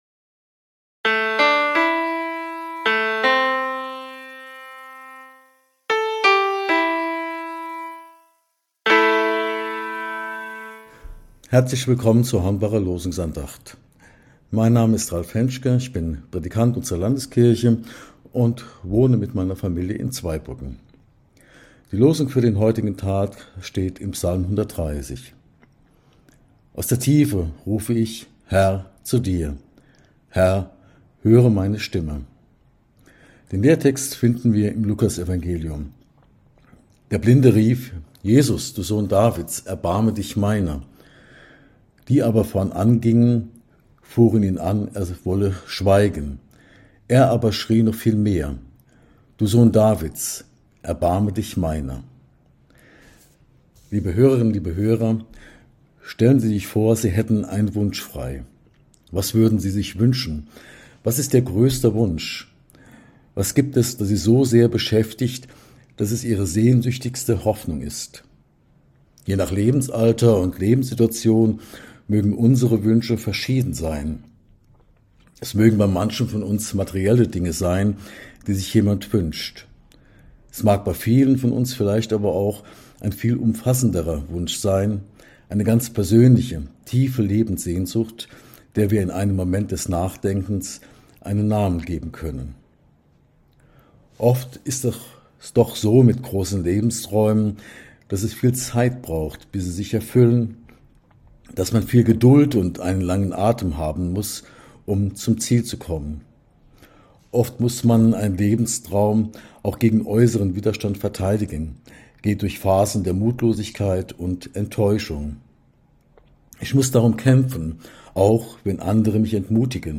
Losungsandacht